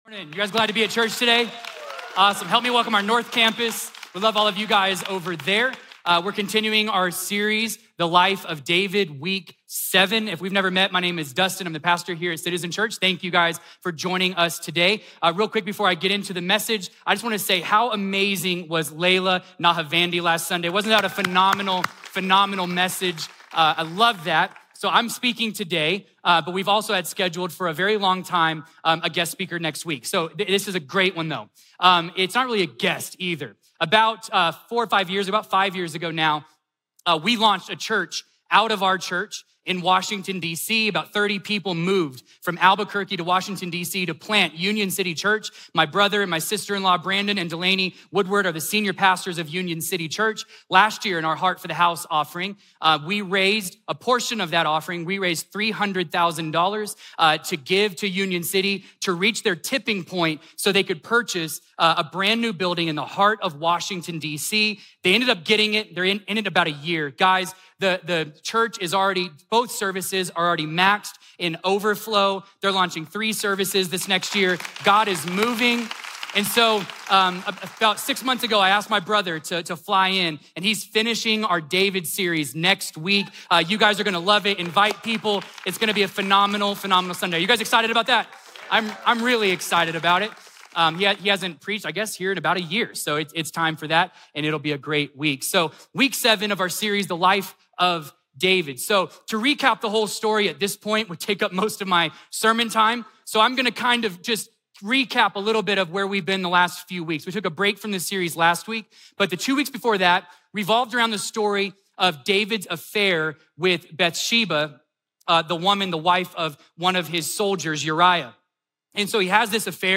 A message from the series "My Part of the Deal."